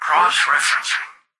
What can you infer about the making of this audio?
"Cross-referencing" excerpt of the reversed speech found in the Halo 3 Terminals. H3_tvox_hex7_crossreferencing_(unreversed).mp3